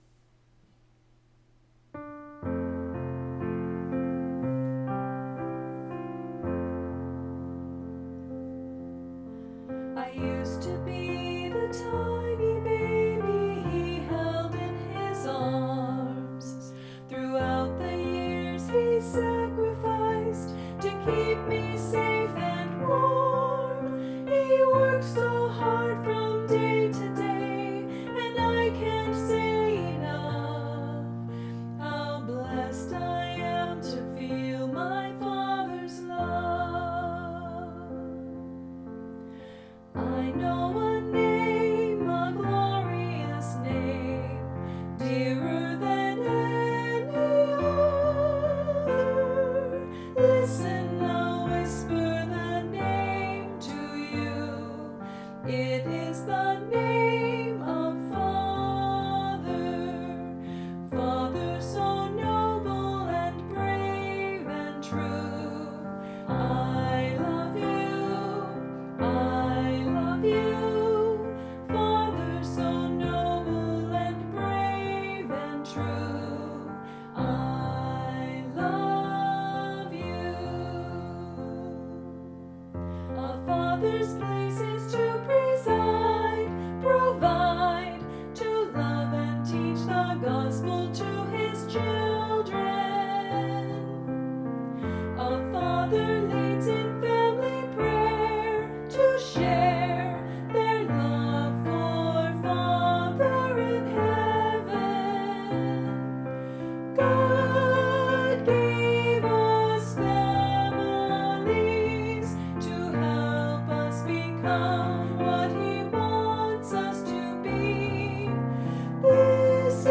Voicing/Instrumentation: Primary Children/Primary Solo